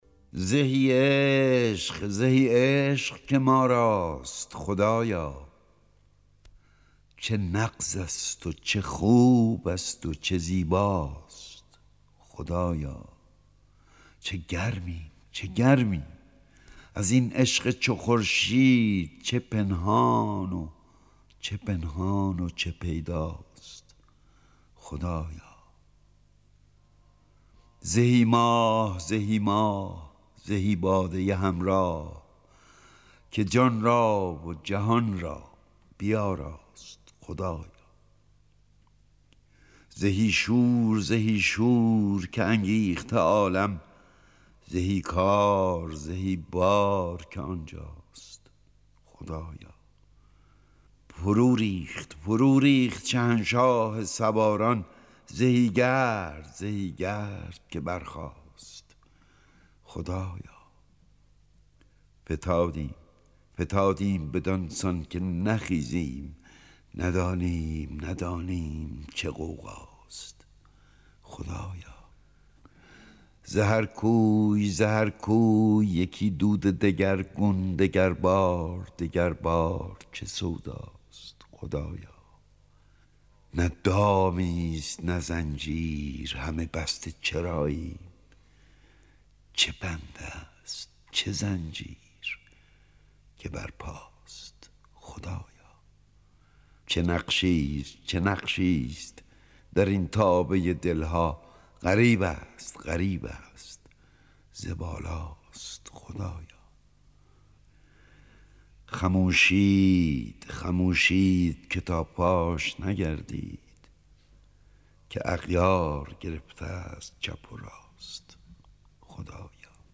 دانلود دکلمه زهی عشق، زهی عشق که ماراست خدایا با صدای احمد شاملو
اطلاعات دکلمه
گوینده :   [احمد شاملو]